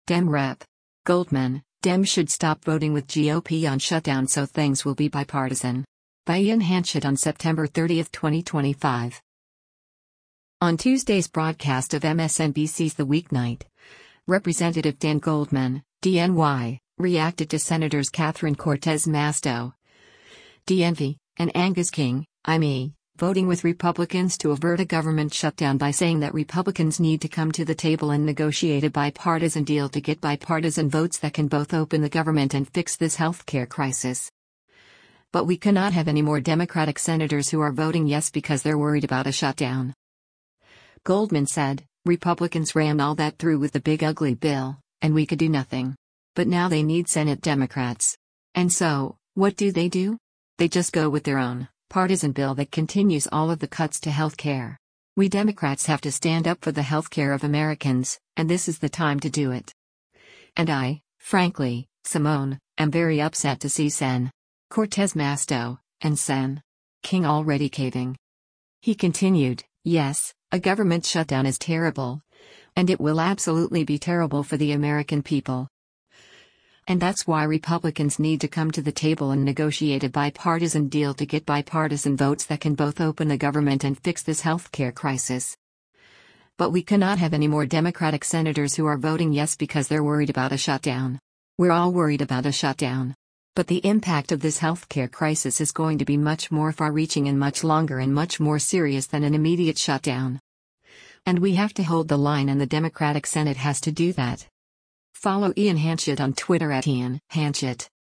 On Tuesday’s broadcast of MSNBC’s “The Weeknight,” Rep. Dan Goldman (D-NY) reacted to Sens. Catherine Cortez Masto (D-NV) and Angus King (I-ME) voting with Republicans to avert a government shutdown by saying that “Republicans need to come to the table and negotiate a bipartisan deal to get bipartisan votes that can both open the government and fix this healthcare crisis. But we cannot have any more Democratic senators who are voting yes because they’re worried about a shutdown.”